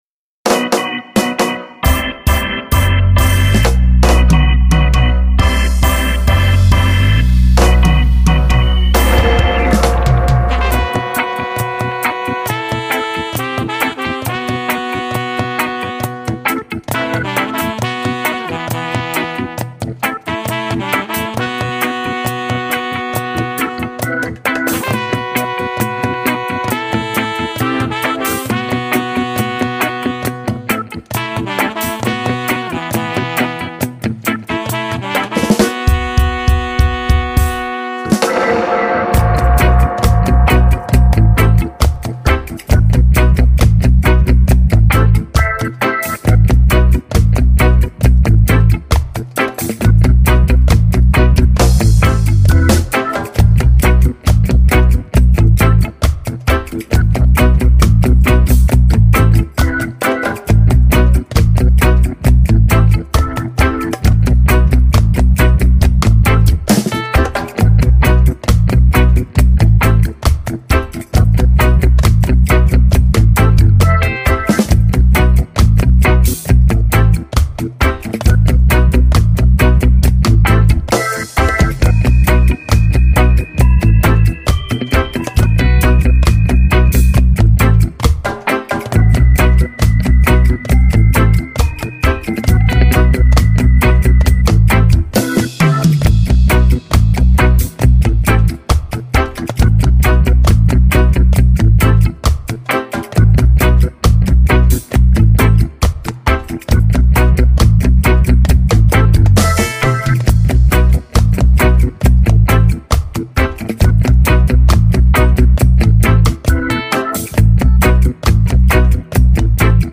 Drum
Bass
Guitar Rythm.
Organ
Sax